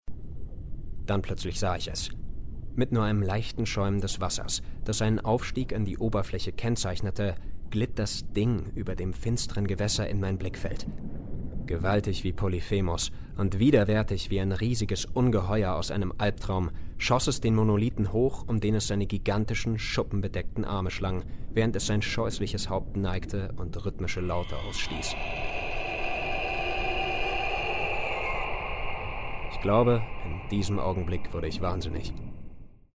Erklärfilm - TED-Ed Die Geheimnisse des Pascalschen Dreiecks